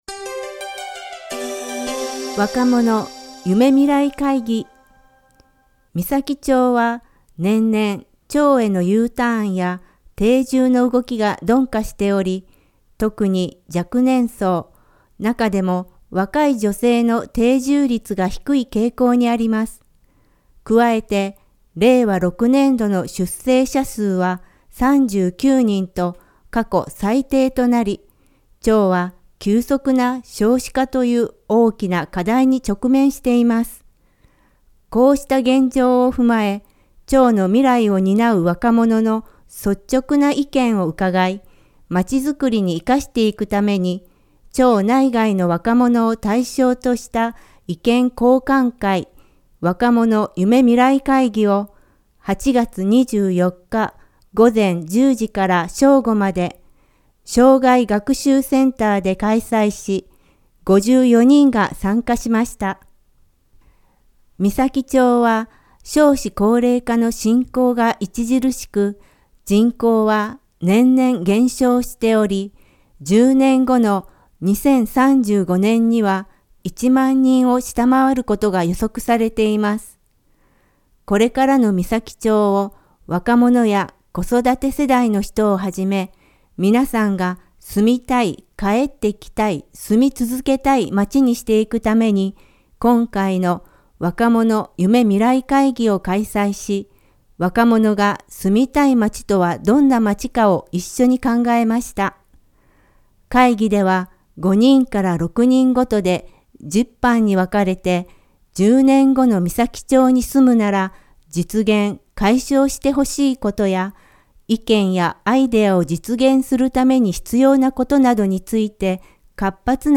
声の広報（広報紙の一部を読み上げています）